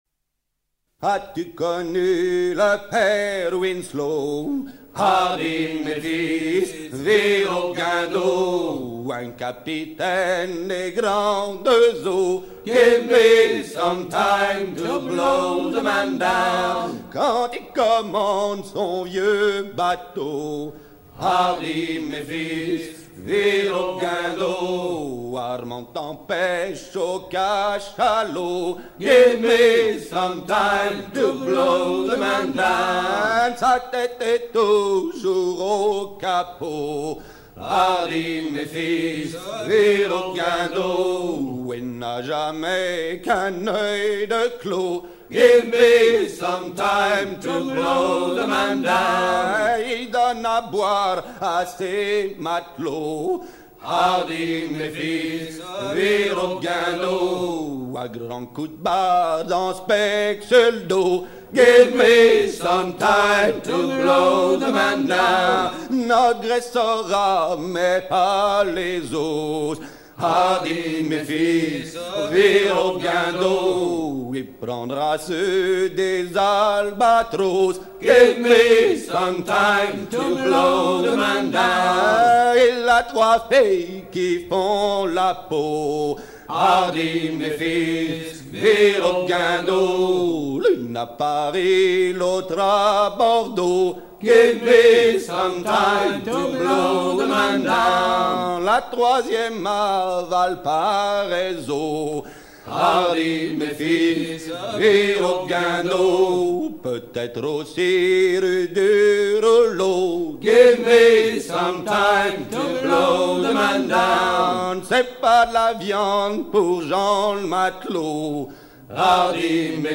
gestuel : à virer au cabestan
Genre laisse
Pièce musicale éditée